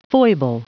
Prononciation du mot foible en anglais (fichier audio)
Prononciation du mot : foible
foible.wav